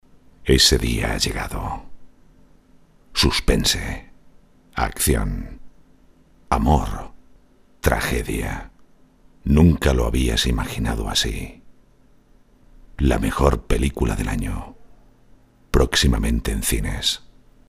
Kein Dialekt
Sprechprobe: Industrie (Muttersprache):